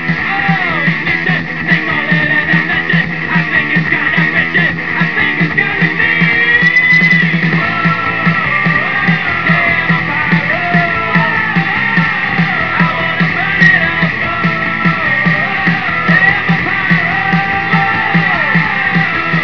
Unfortunately, the clips are not of best quality.